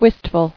[wist·ful]